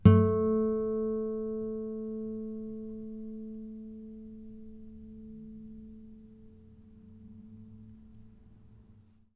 harmonic-01.wav